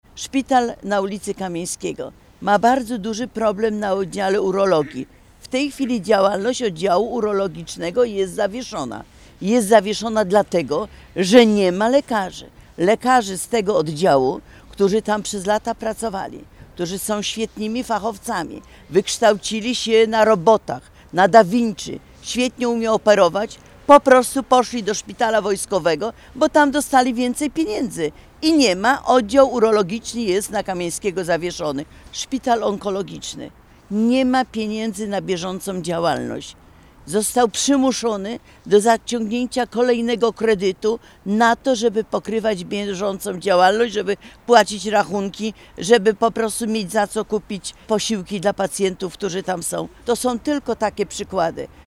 Wiceprezes PiS Elżbieta Witek, a także posłowie Paweł Hreniak, Jacek Świat oraz radni Sejmiku Województwa Dolnośląskiego spotkali się przy szpitalu uniwersyteckim we Wrocławiu, by zaapelować do rządu o podjęcie działań ws. ochrony i służby zdrowia.
Jako przykład niewydolności podano Wojewódzki Szpital Specjalistyczny przy ulicy Kamieńskiego we Wrocławiu. Z powodu braku obsady lekarskiej pół roku temu zamknięto tam oddział urologii, a lekarze specjaliści przeszli do szpitala wojskowego na Weigla, mówi Małgorzata Calińska-Mayer, radna Sejmiku Województwa Dolnośląskiego.